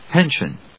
音節pen・sion 発音記号・読み方
/pénʃən(米国英語), pˈɔŋsjɔŋ(英国英語)/